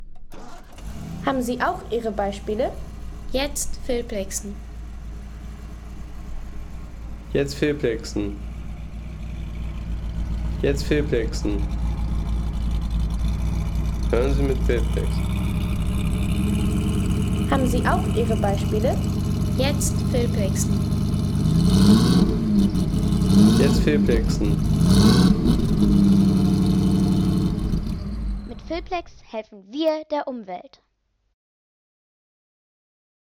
Das Knurren eines Achtzylindermotors von 1932 – Stand, Drehzahl und ... 5,50 € Inkl. 19% MwSt.